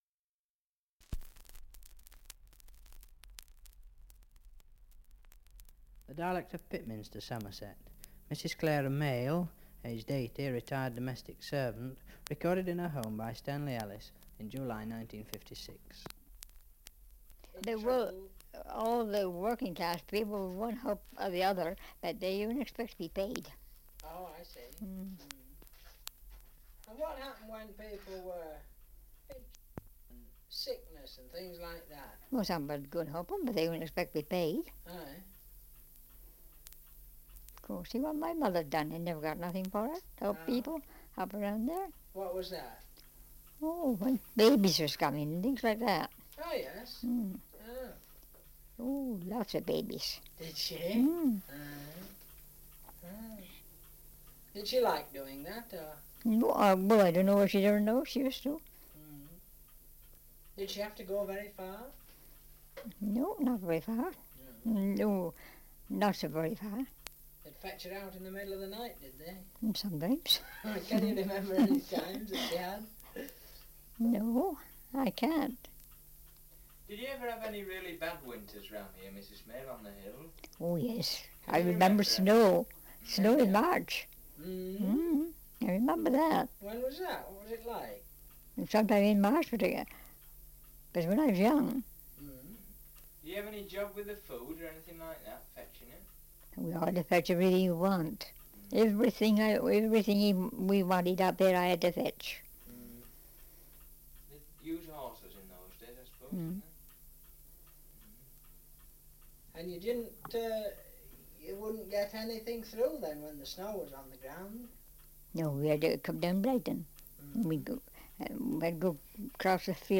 Survey of English Dialects recording in Pitminster, Somerset
78 r.p.m., cellulose nitrate on aluminium